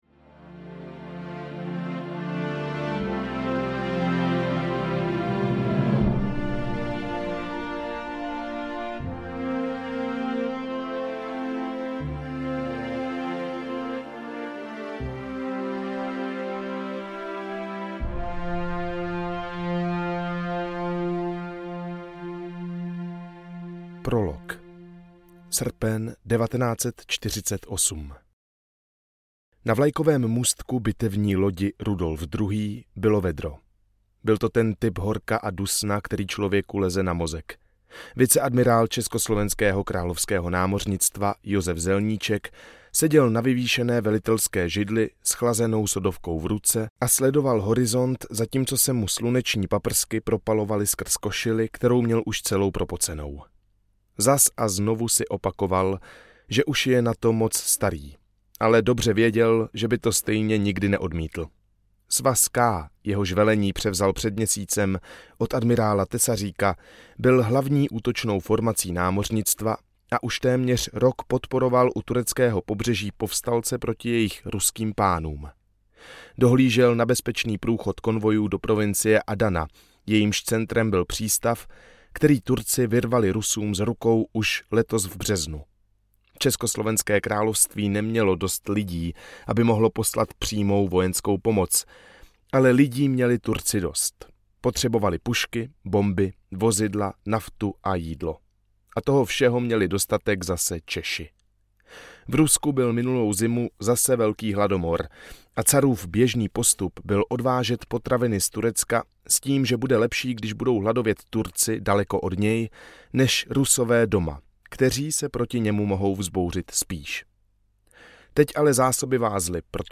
Spojenci Českých zemí audiokniha
Ukázka z knihy